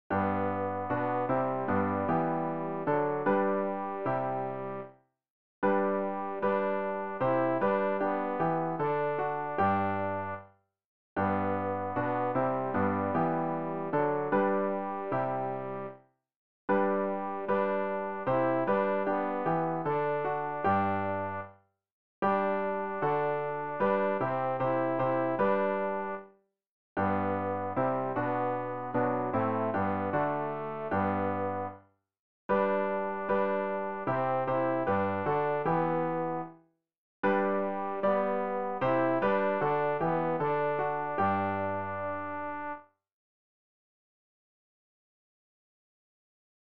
tenor-rg-020-ich-erhebe-mein-gemuete.mp3